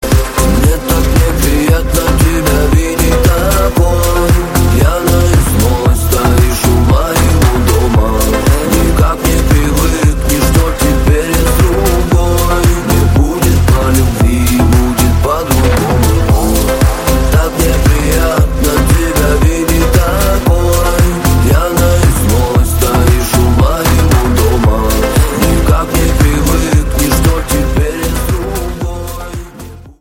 • Качество: 128, Stereo
дуэт